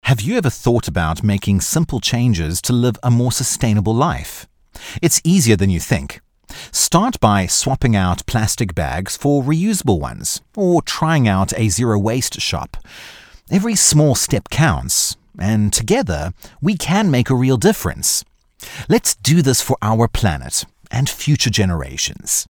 Erklärvideos
Herzlich, sanft und vielseitig.